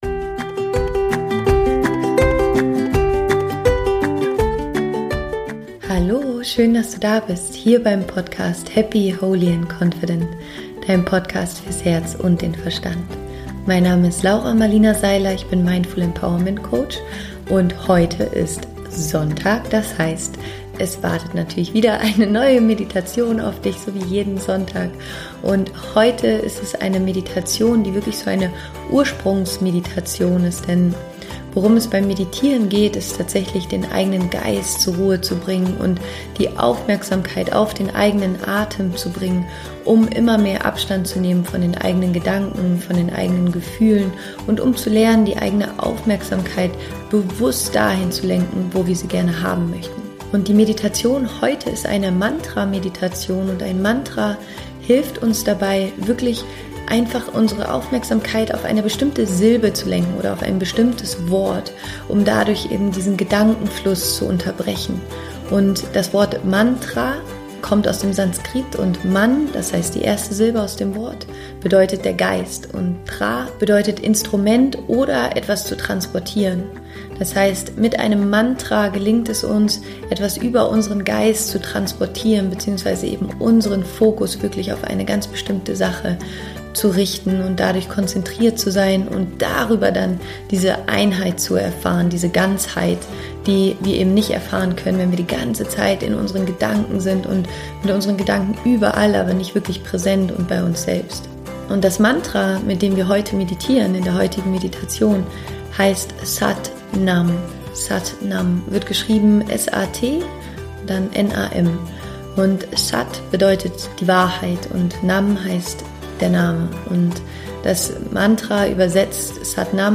Meditation: Verbinde dich mit deiner Wahrheit
Finde für die Meditation einen aufrechten und bequemen Sitz, schließe deine Augen und lass dich von mir durch die Meditation führen.